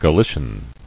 [Ga·li·cian]